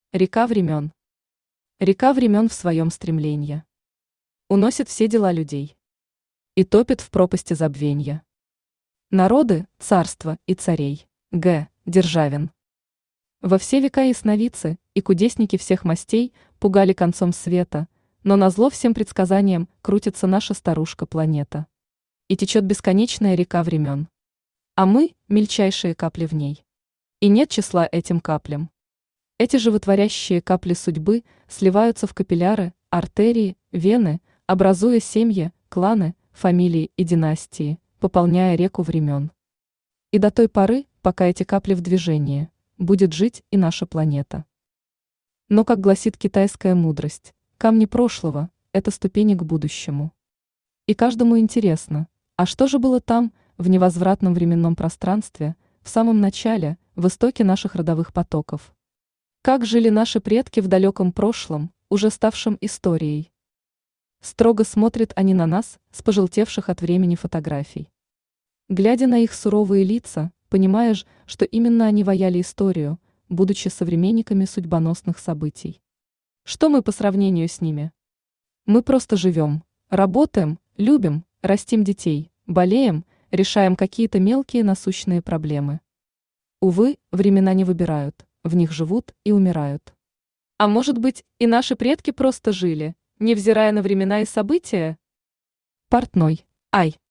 Аудиокнига Река времен. Портной | Библиотека аудиокниг
Портной Автор Вера Капьянидзе Читает аудиокнигу Авточтец ЛитРес.